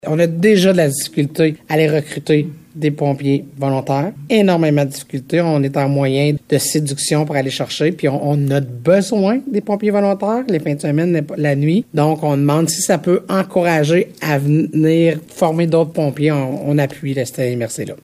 Cette réalité est vécue ici, dans la Vallée-de-la-Gatineau, comme l’explique la préfète, Chantal Lamarche :